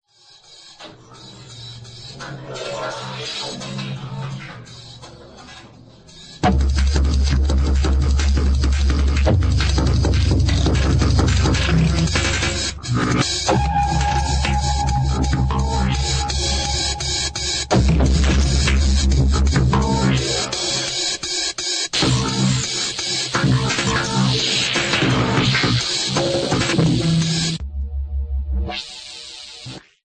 Crazy electronix